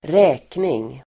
Uttal: [²r'ä:kning]